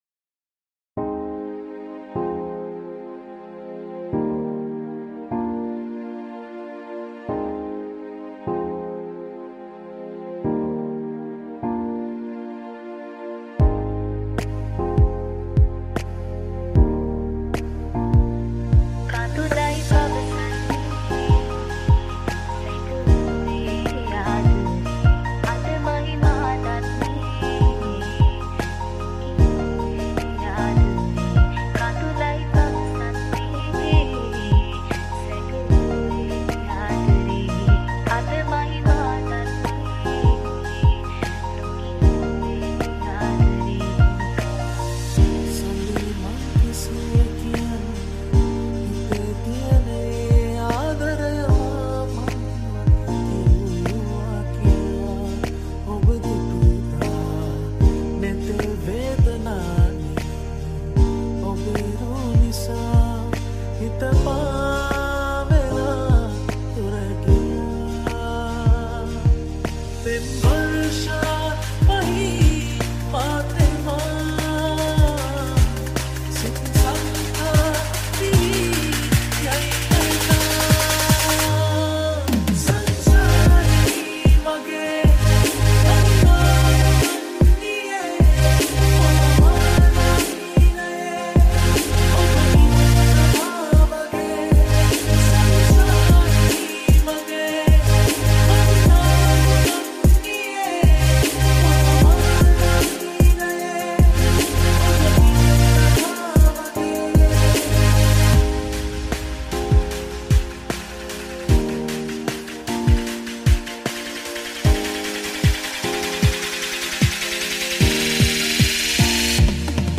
Future Bass Remix